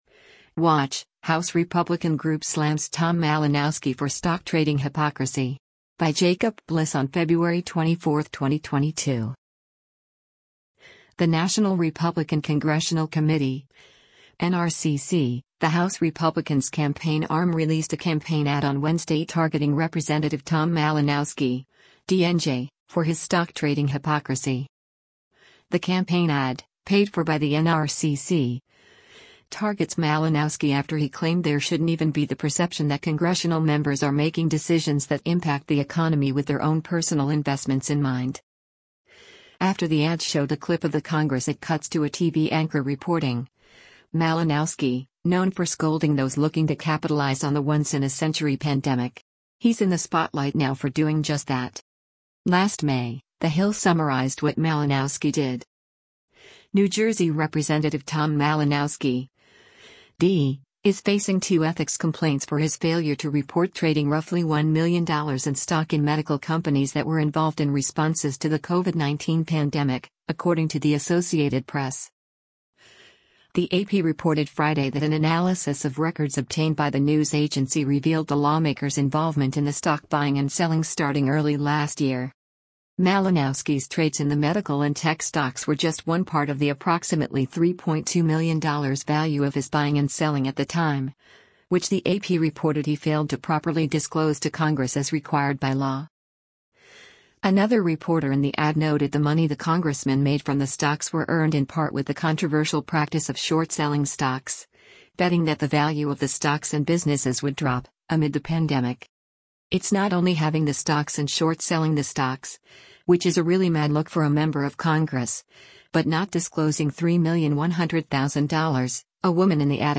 The National Republican Congressional Committee (NRCC), the House Republicans’ campaign arm released a campaign ad on Wednesday targeting Rep. Tom Malinowski (D-NJ) for his stock trading hypocrisy.
After the ad showed a clip of the congress it cuts to a TV anchor reporting, “Malinowski, known for scolding those looking to capitalize on the once-in-a-century pandemic. He’s in the spotlight now for doing just that.”